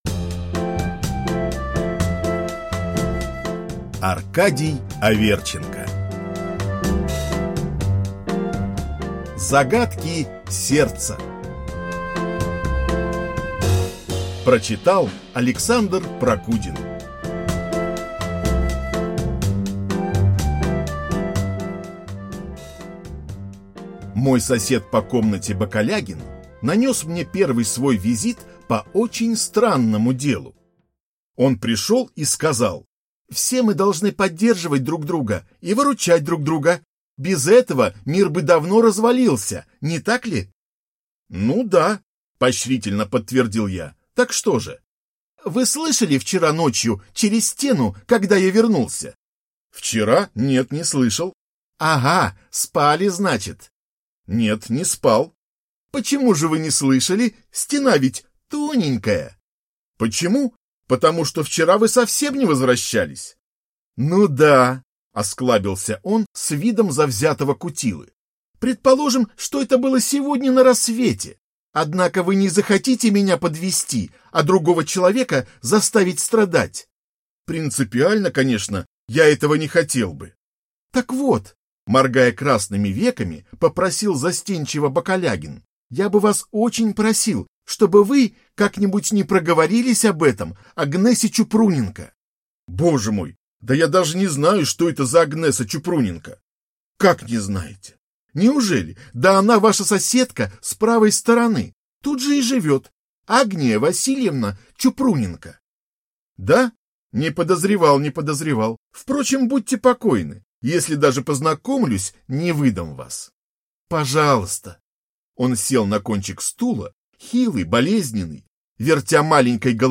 Аудиокнига Загадки сердца | Библиотека аудиокниг